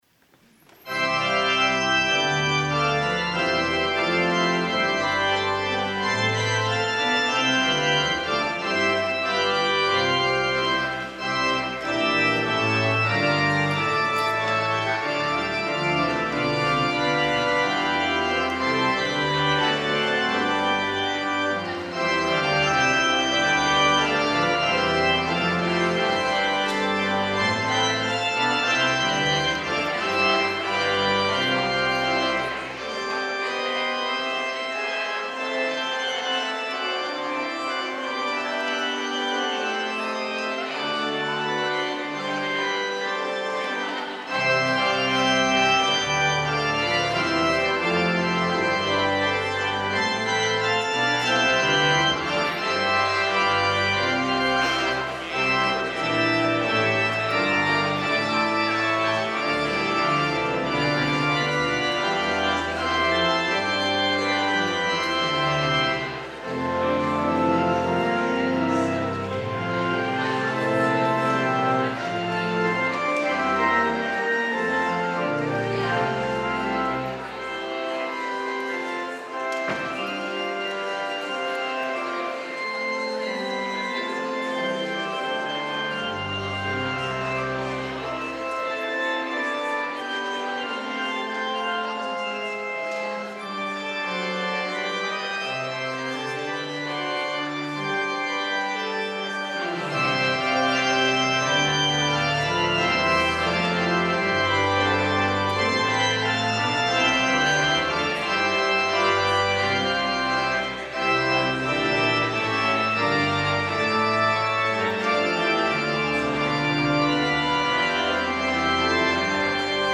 POSTLUDE Choral Song Samuel Sebastian Wesley
organ